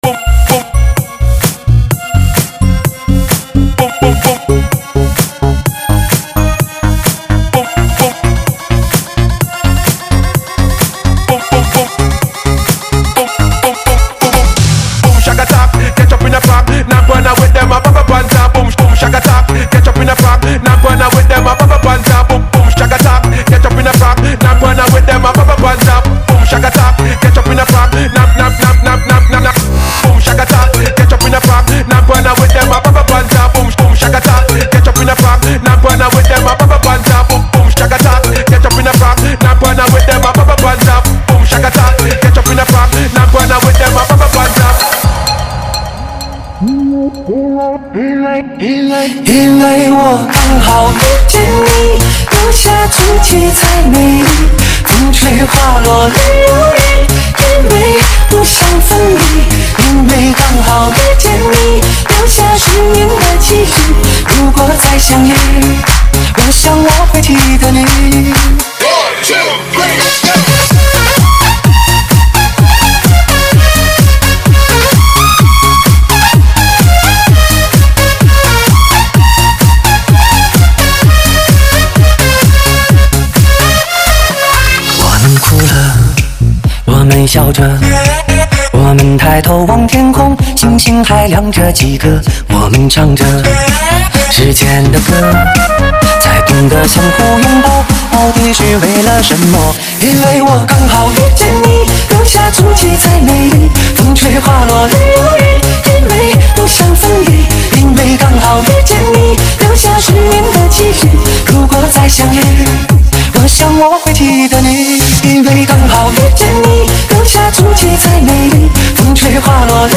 时尚电音 劲爆激情车载舞曲 全欧陆震撼动感的好听音乐